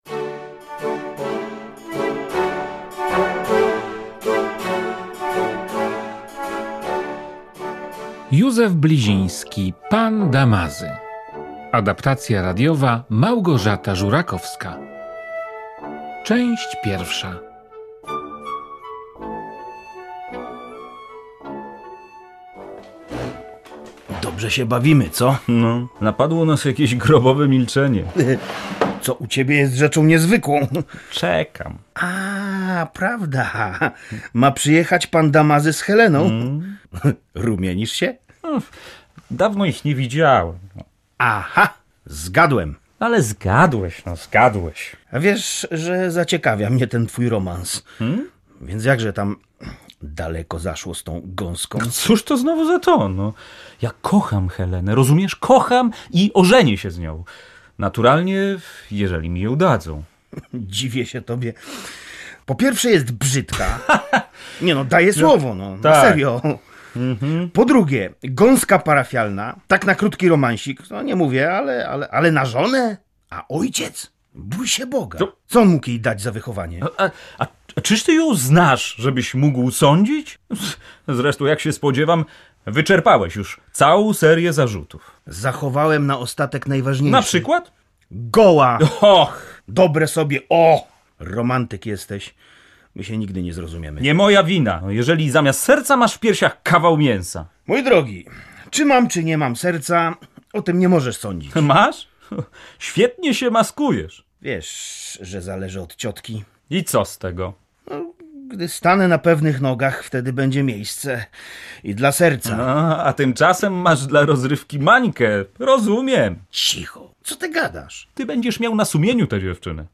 W programie słuchowisko na podstawie sztuki Józefa Blizińskiego (na zdj.) „Pan Damazy”.